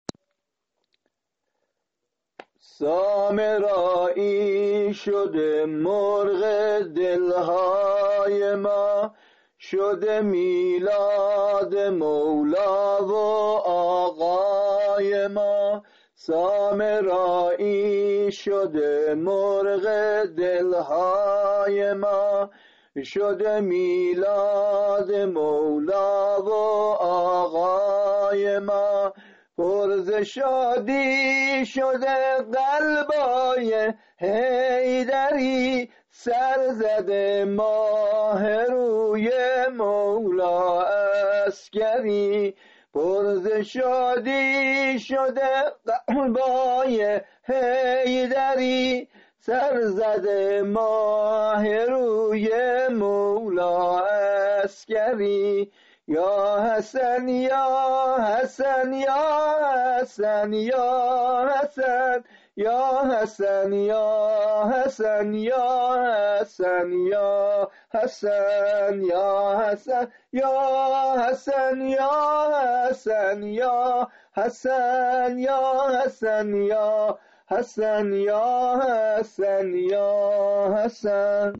سرود میلاد امام عسکری